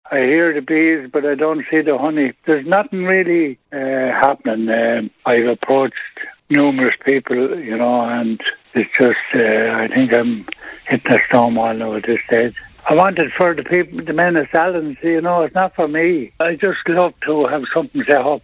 Speaking to the 'Kildare Today' programme